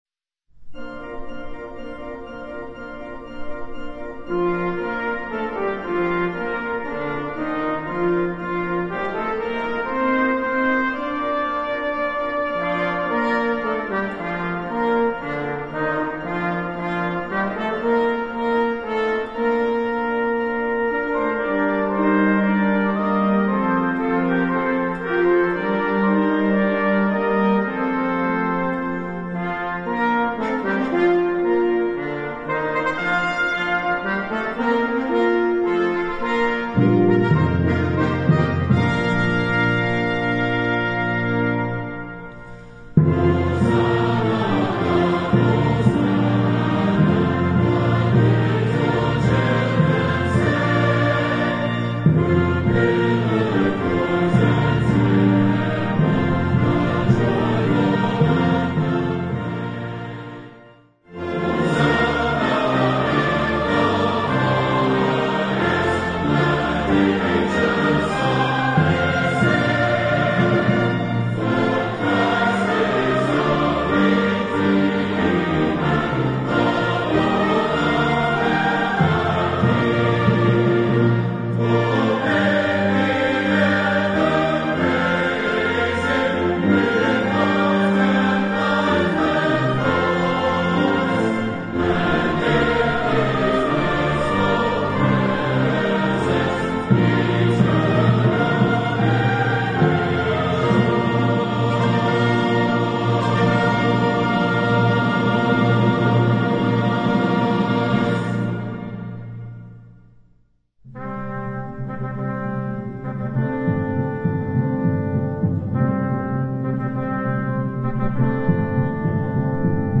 Voicing: Congregation, Optional Soprano Descant